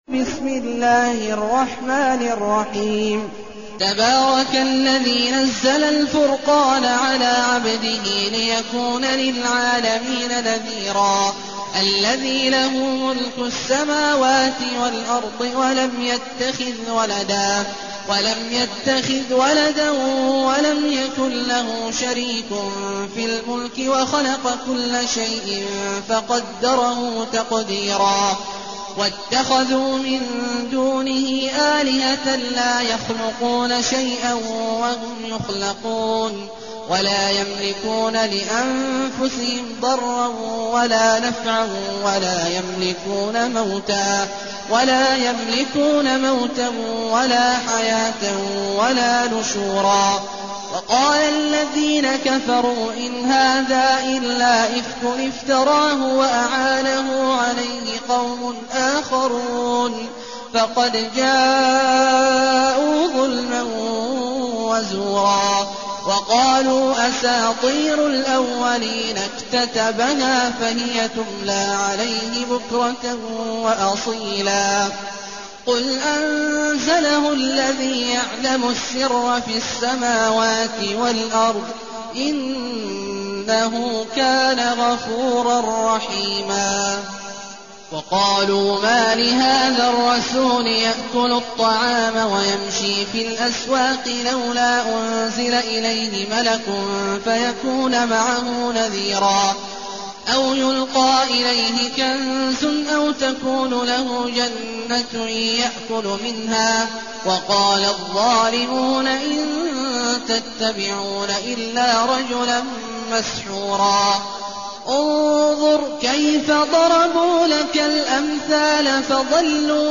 المكان: المسجد النبوي الشيخ: فضيلة الشيخ عبدالله الجهني فضيلة الشيخ عبدالله الجهني الفرقان